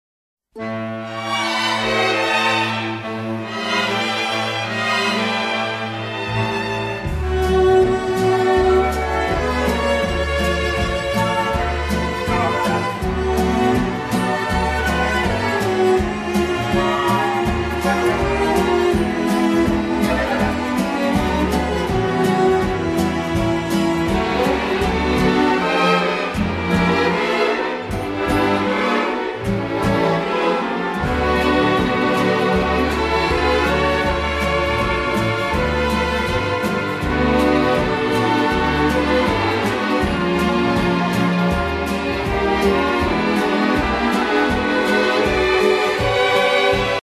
Foxtrott